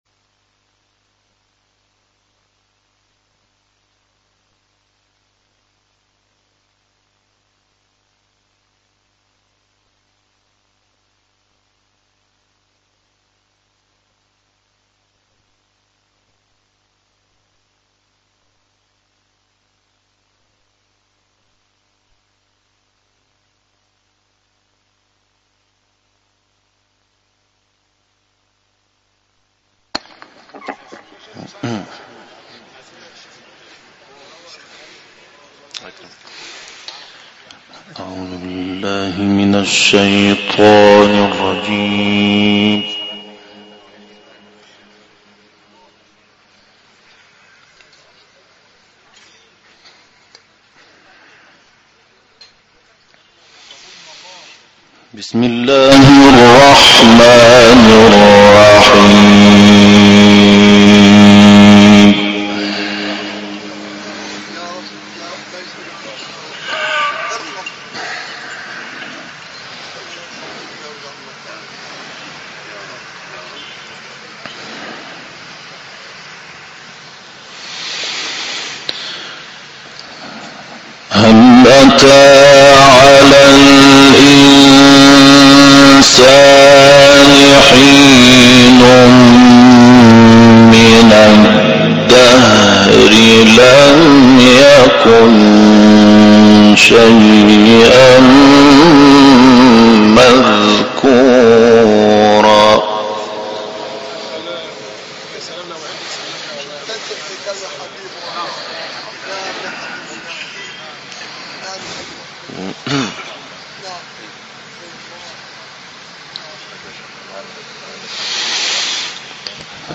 تلاوتی زیبا از «محمود شحات انور» در مصر + صوت
گروه فعالیت‌های قرآنی: محمود شحات‌انور، قاری برجسته مصری هفته گذشته در محفلی قرآنی در مصر آیاتی از سوره «انسان» و «اعلی» را تلاوت کرد.